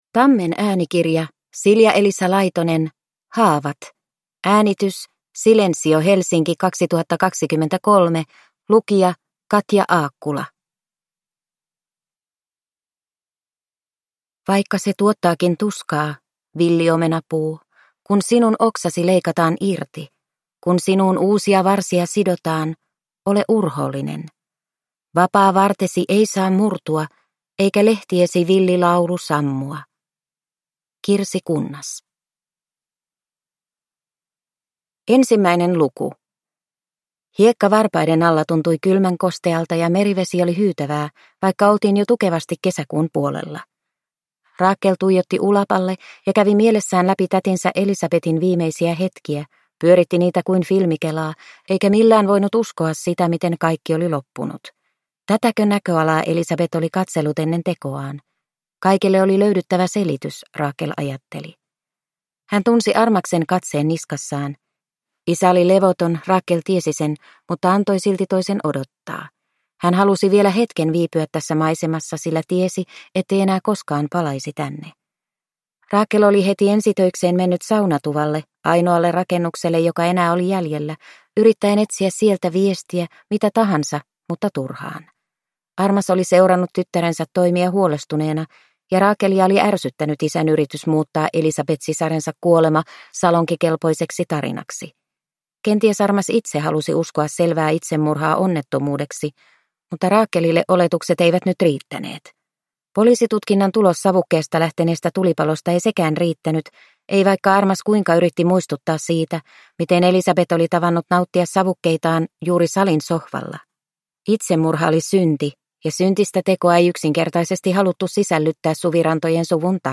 Haavat – Ljudbok – Laddas ner